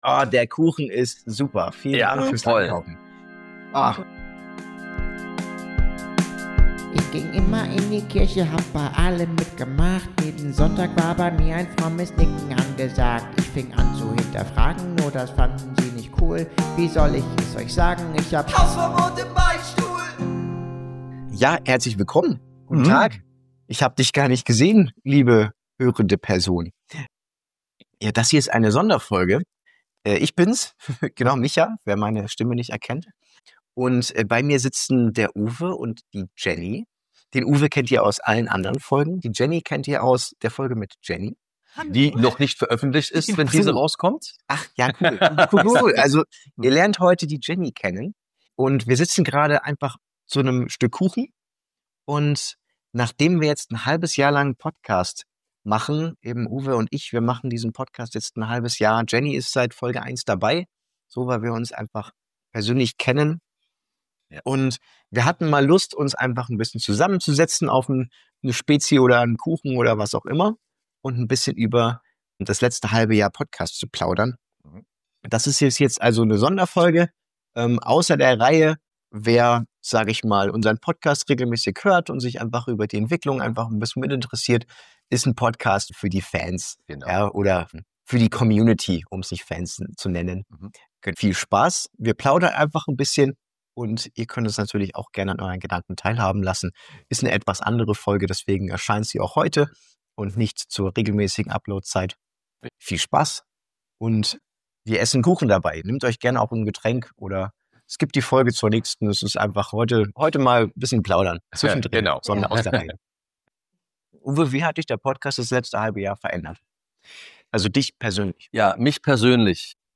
Die Episode endet mit einem Ausblick auf die Zukunft des Podcasts und der Hoffnung, dass die Geschichten der Gäste auch andere inspirieren können. Machts euch gemütlich für ein sehr berührendes, tiefes, ehrliches und mutiges Gespräch!